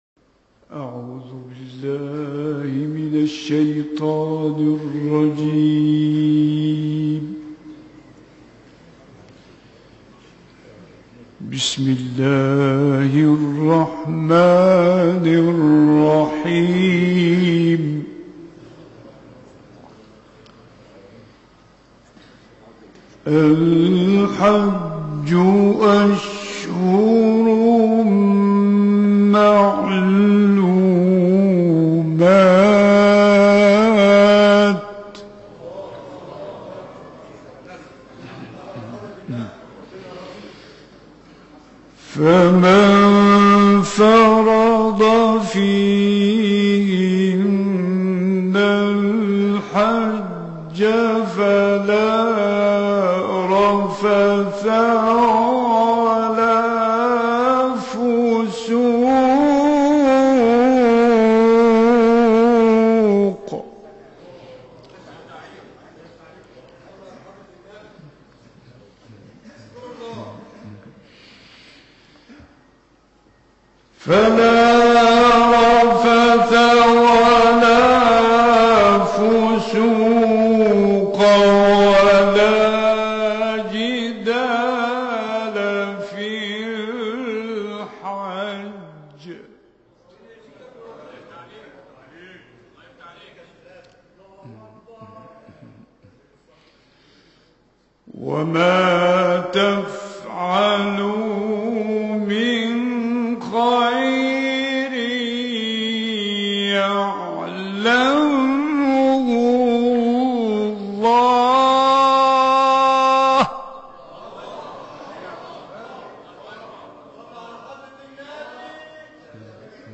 تلاوت کوتاه «غلوش» در مسجد ابراهیم الدوسوقی
این تلاوت کوتاه در مسجد ابراهیم الدوسوقی مصر اجرا شده است.